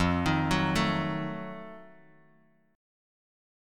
F7b9 Chord
Listen to F7b9 strummed